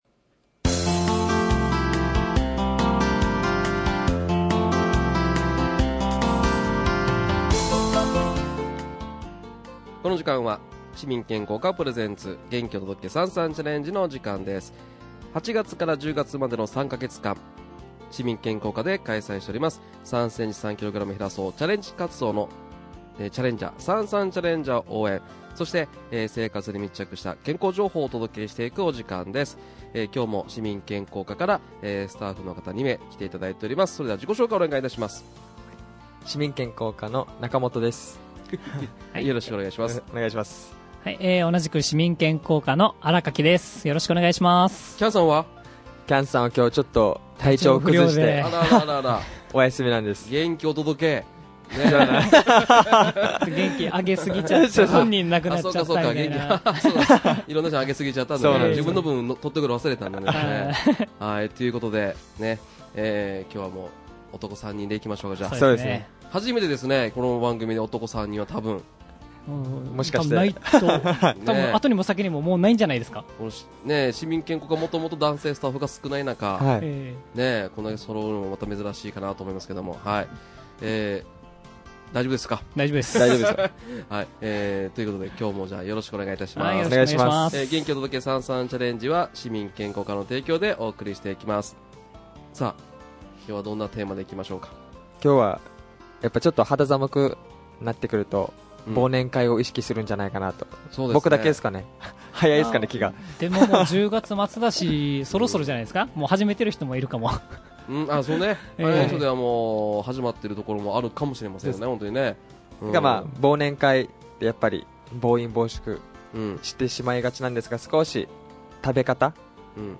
初の全員男性、男3名でお送りしました。